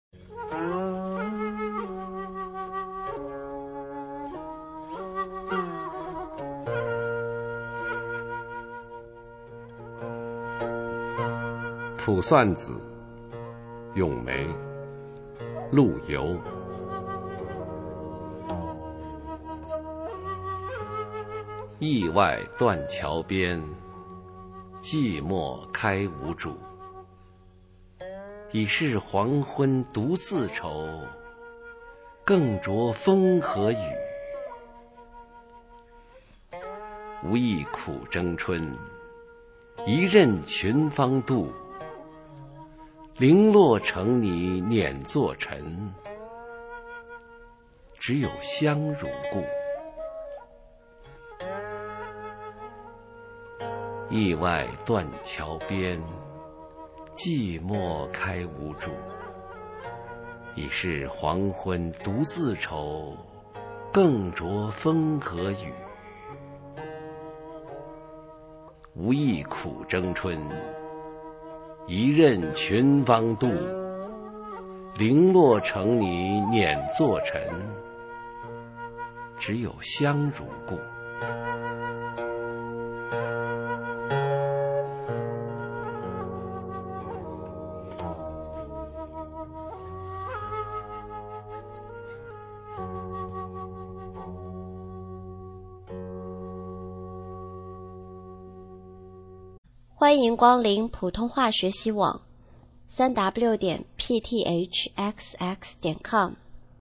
首页 视听 学说普通话 美声欣赏
普通话美声欣赏：卜算子-咏梅　/ 佚名